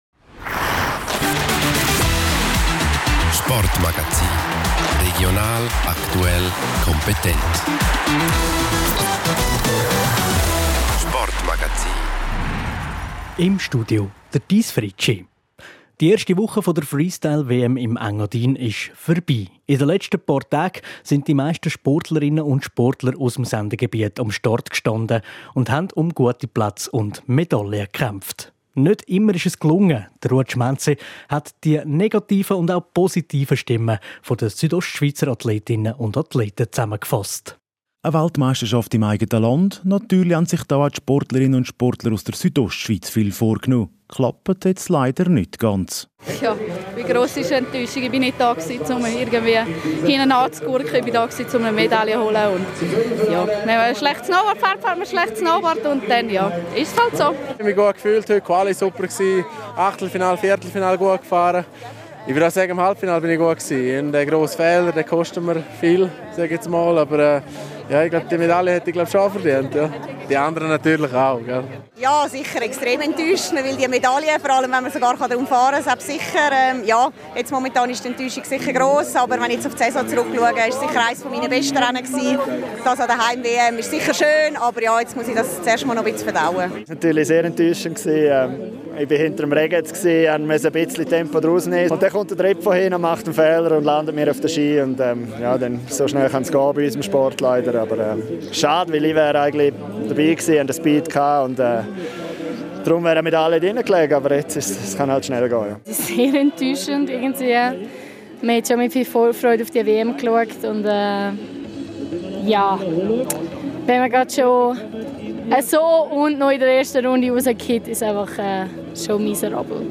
Eine Woche Freestyle-WM: Eine Collage mit negativen und positiven Reaktionen
Wir haben eine Collage mit den negativen und auch positiven Stimmen zusammengeschnitten.